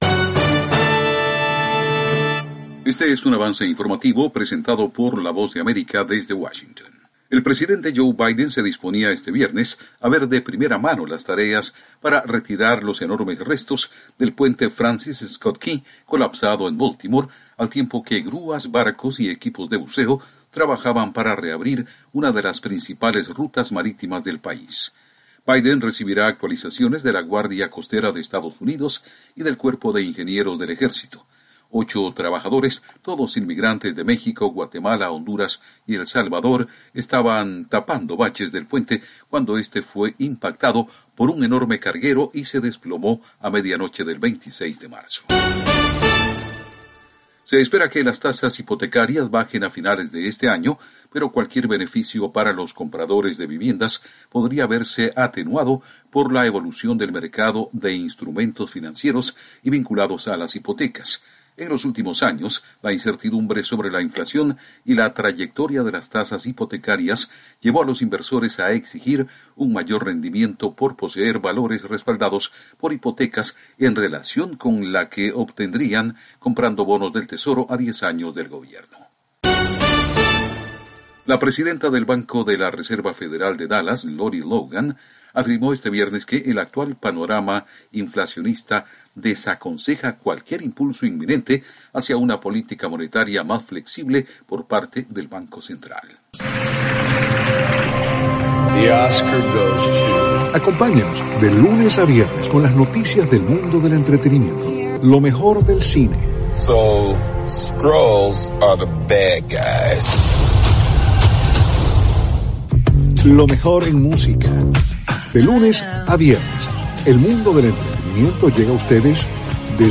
Avance Informativo 4:00 PM
El siguiente es un avance informative presentado por la Voz de America en Washington